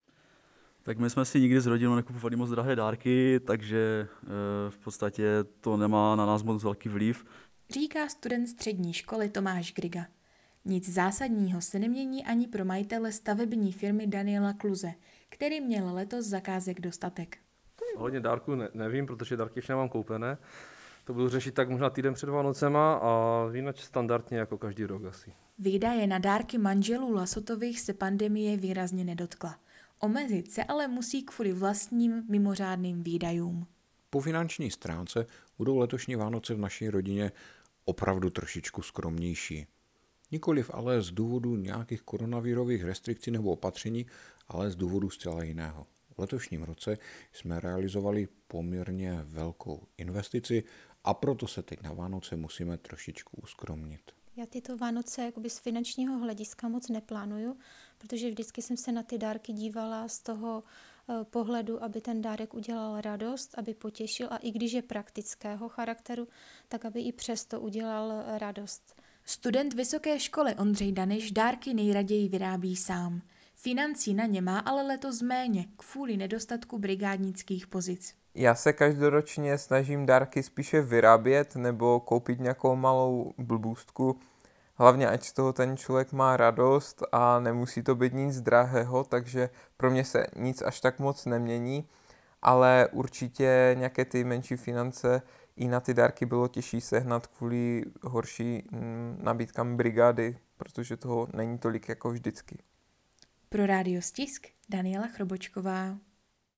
Anketa: Většina Čechů letos plánuje úspornější Vánoce